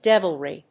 音節dev・il・ry 発音記号・読み方
/dév(ə)lri(米国英語), ˈdɛv.əl.ri(英国英語)/